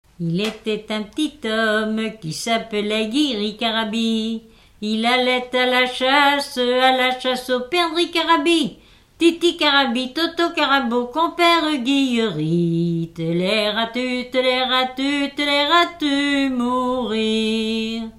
Enfantines - rondes et jeux
Enquête Arexcpo en Vendée-C.C. Talmont
Pièce musicale inédite